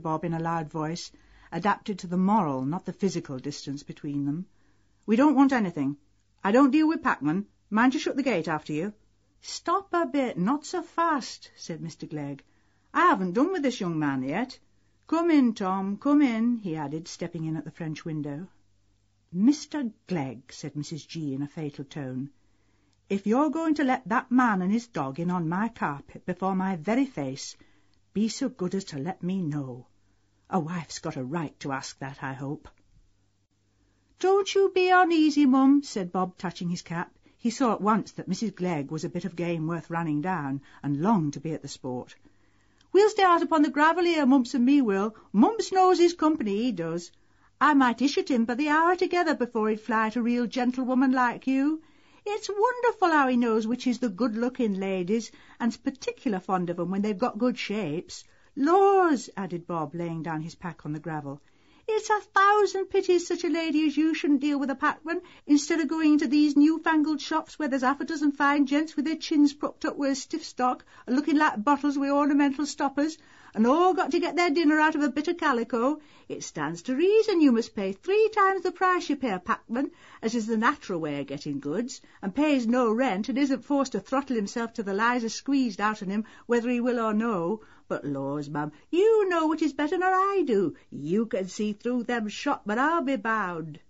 Eileen Atkins reading Mill on the Floss, scene with Bob Jakin and Aunt Glegg Mary Shelley in Frankenstein on Werther